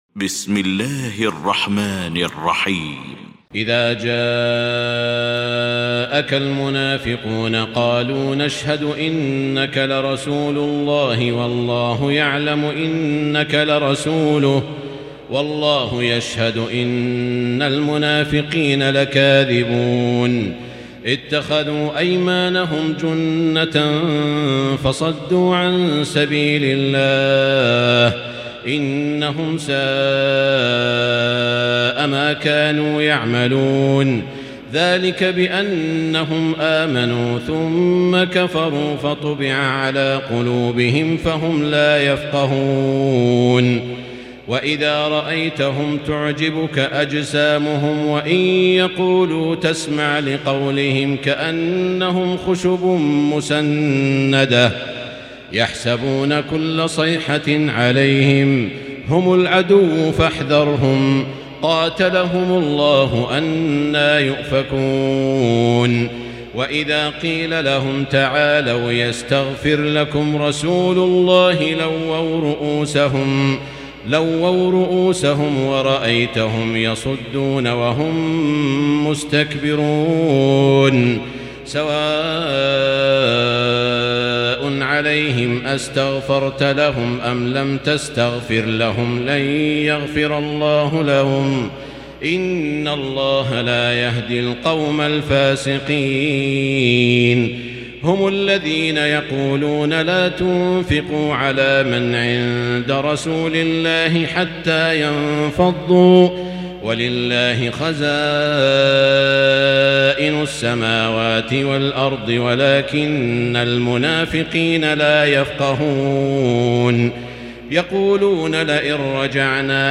المكان: المسجد الحرام الشيخ: سعود الشريم سعود الشريم المنافقون